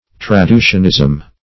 Search Result for " traducianism" : The Collaborative International Dictionary of English v.0.48: Traducianism \Tra*du"cian*ism\, n. (Theol.) The doctrine that human souls are produced by the act of generation; -- opposed to creationism, and infusionism.
traducianism.mp3